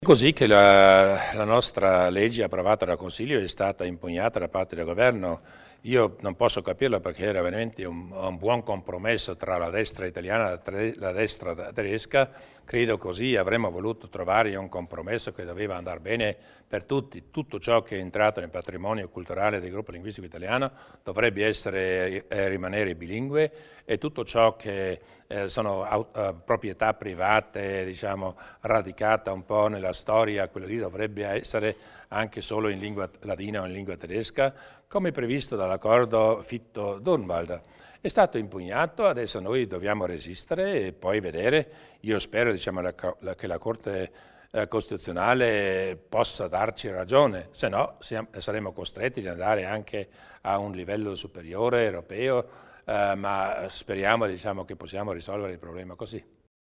Il Presidente Durnwalder spiega i prossimi passi in tema di legge sulla toponomastica